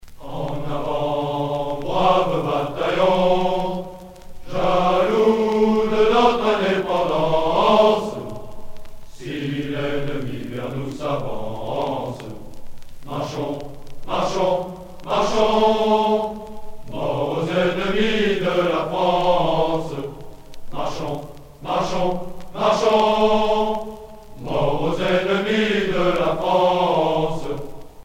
gestuel : à marcher
circonstance : militaire
Genre strophique